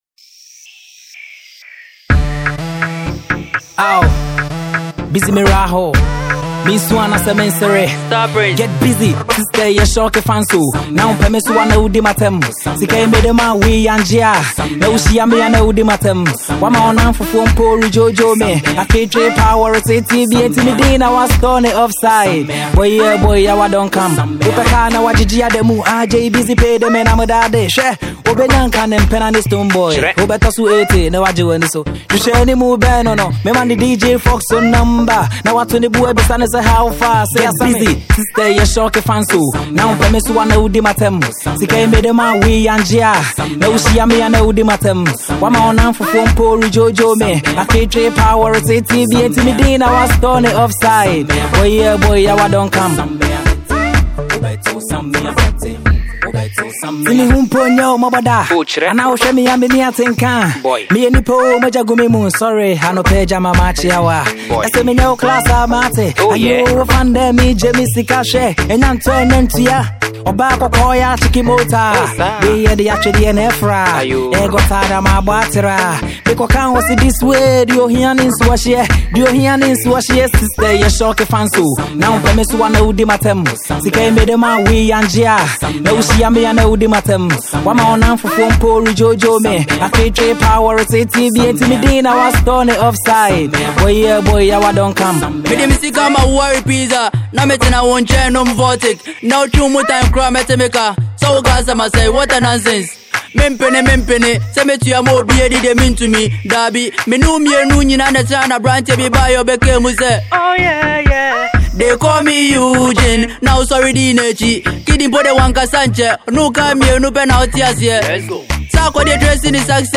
danceable tune